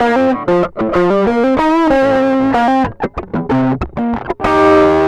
Track 13 - Distorted Guitar Wah 01.wav